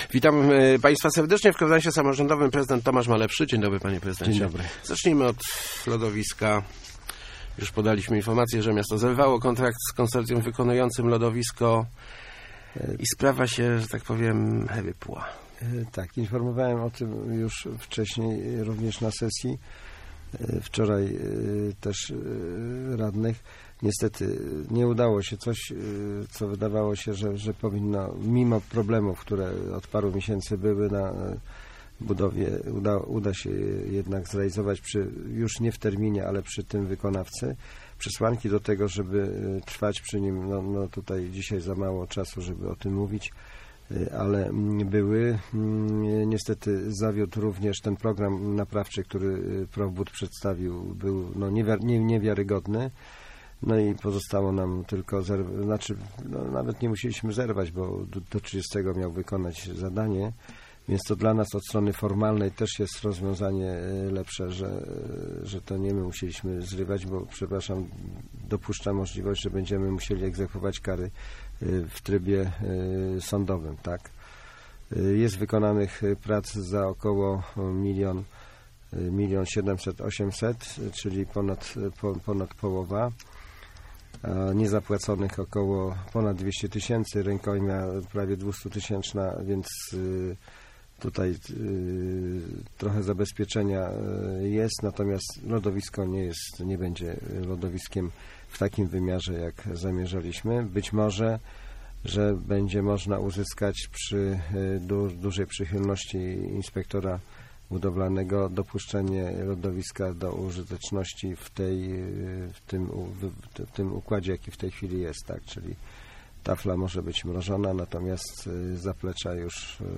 Gościem Kwadransa był prezydent Tomasz Malepszy ...